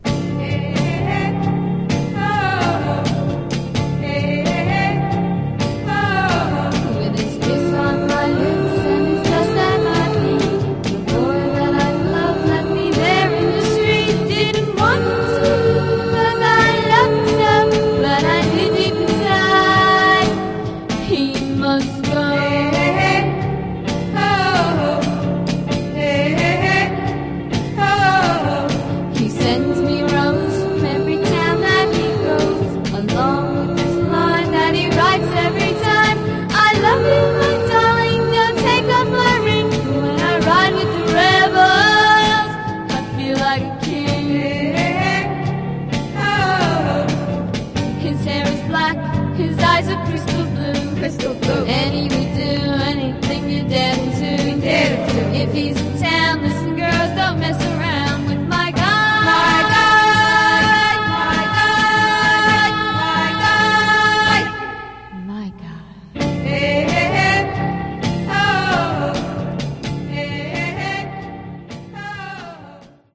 Genre: Garage/Psych